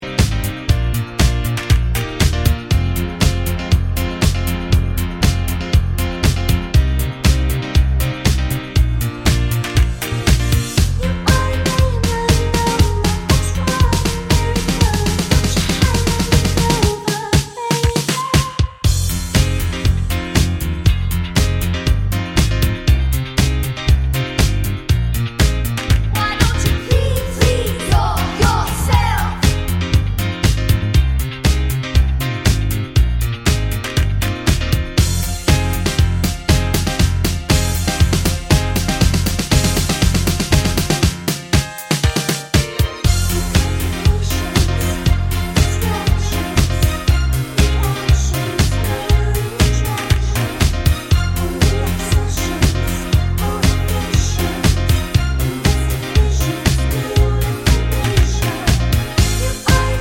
no Backing Vocals Pop (2020s) 3:54 Buy £1.50